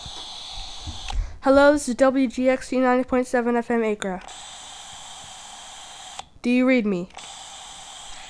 WGXC Walkie-Talkie Station ID (Audio)
STATION ID - WALKIETALKIE.wav